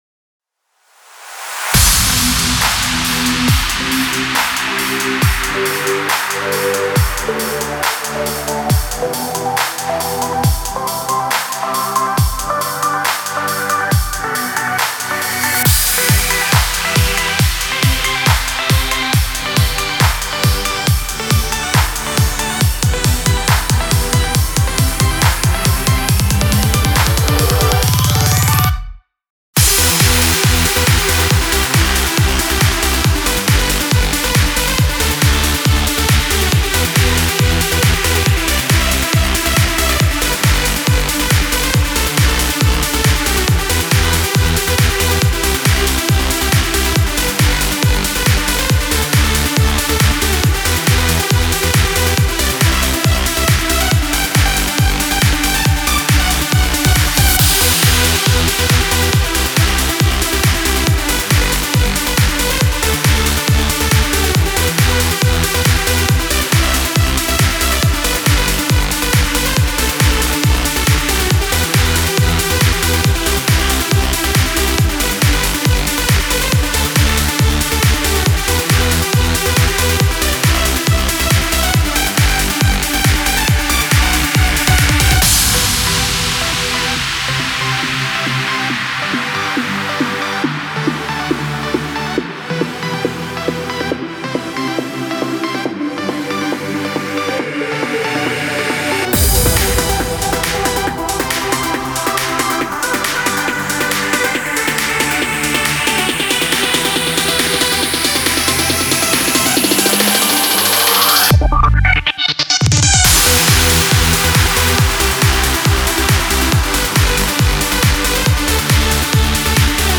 曲構成がわかりやすく編集しやすいEDM・トランス（bpm:138）
EDM・トランス系のシンセ音源を使用して制作。
比較的オーソドックスのサウンドで、映像制作などで使い勝手の良い楽曲になっています。